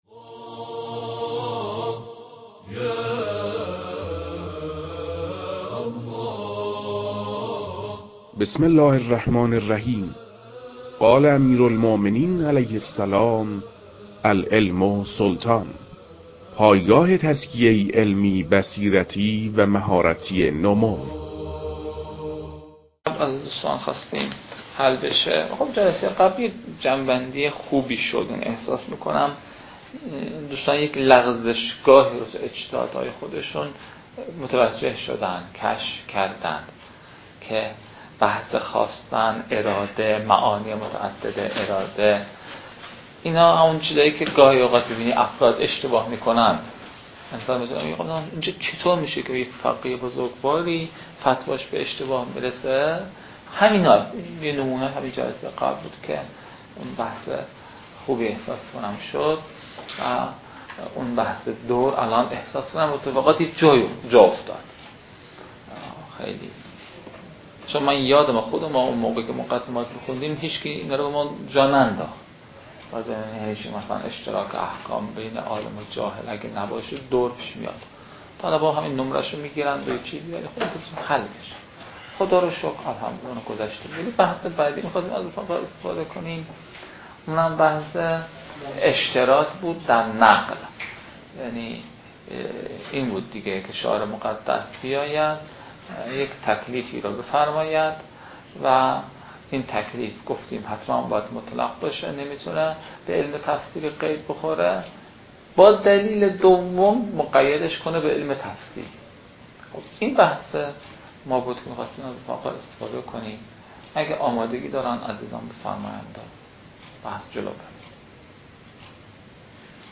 صوتی است از کارگاه